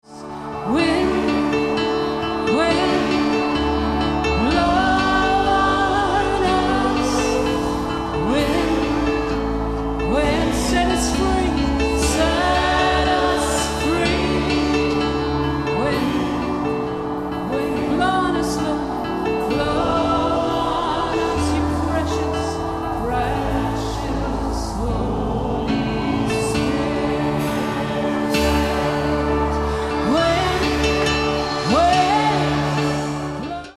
Spontaneous Singing